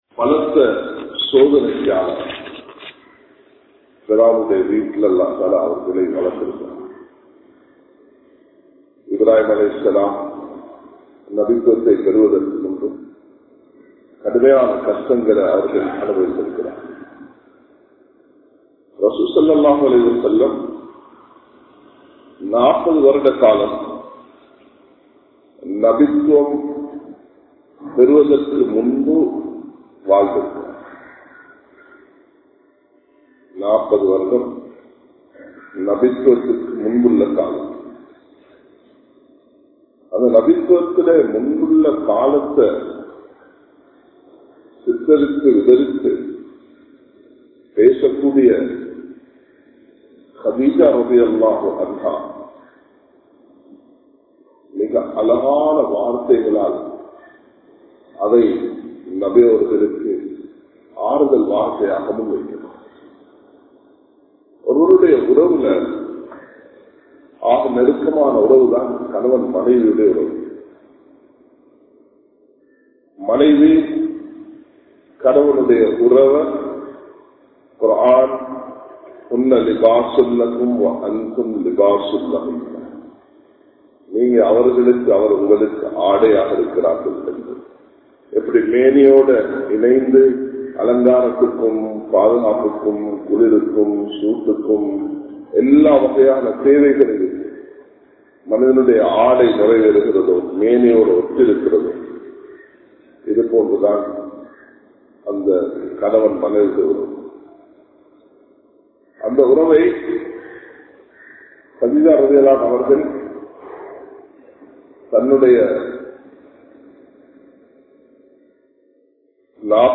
Dheenudaiya Ulaippu (தீணுடைய உழைப்பு) | Audio Bayans | All Ceylon Muslim Youth Community | Addalaichenai